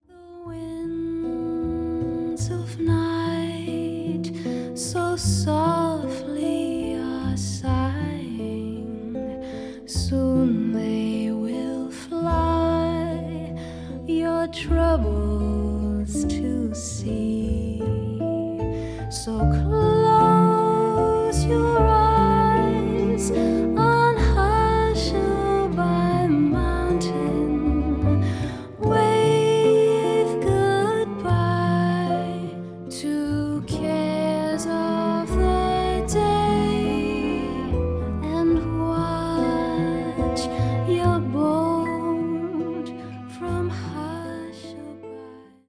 vocal
bass, piano, vibraphones
gutiar
cello